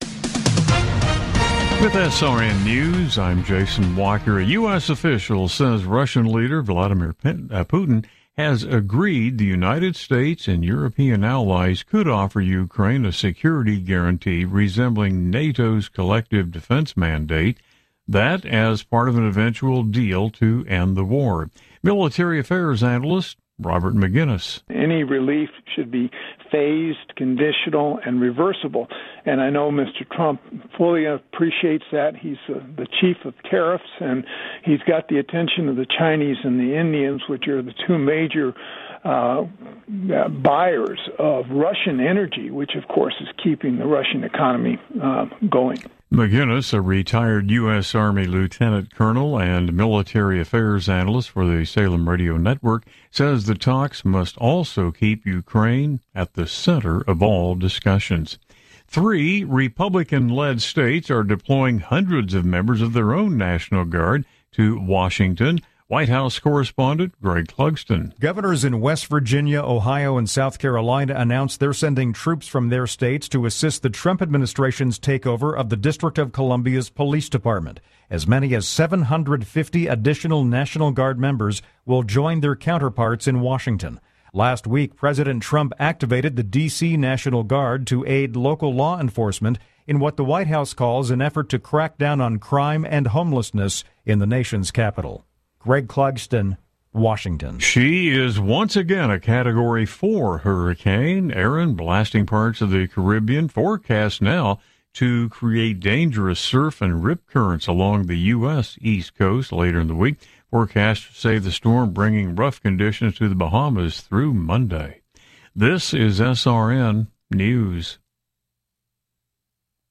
Top News Stories Aug 18, 2025 – 04:00 AM CDT